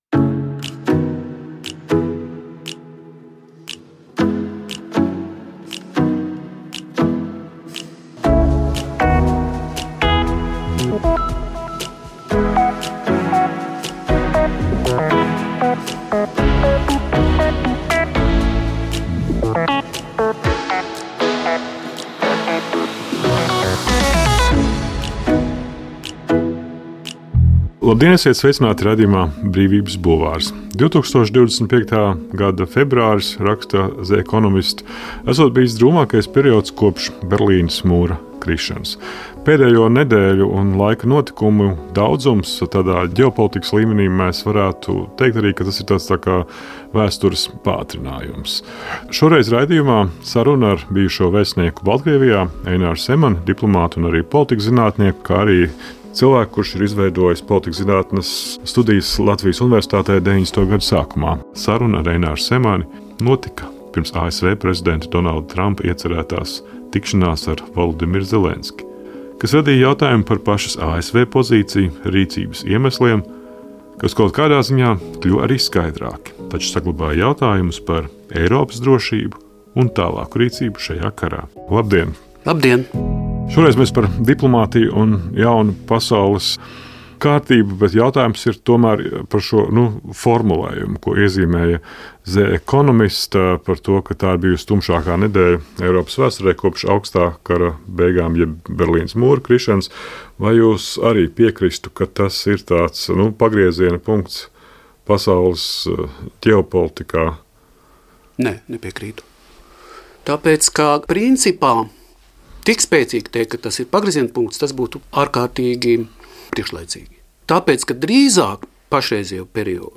Raidījums “Brīvības bulvāris” reizi nedēļā piedāvās tikšanās un sarunas ar cilvēkiem, kuru domas un idejas liek varbūt mums pašiem kļūt gudrākiem.